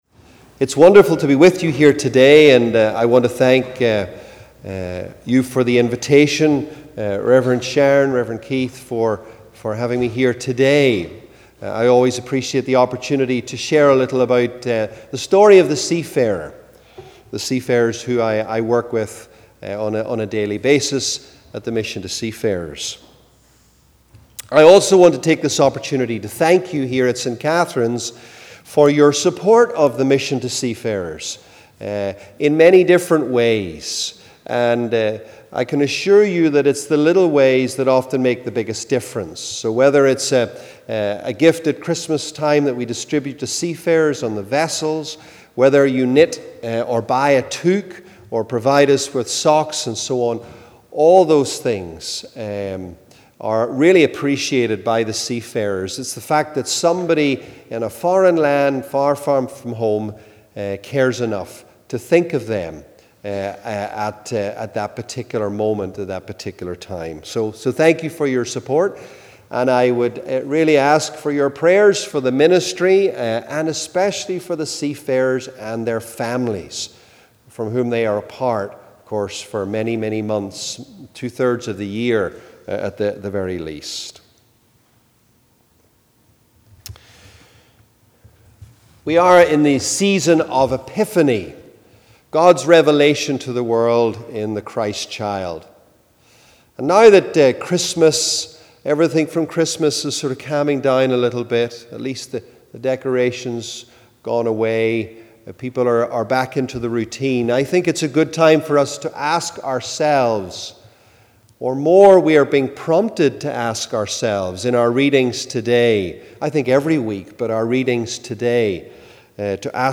Second Sunday after the Epiphany 2018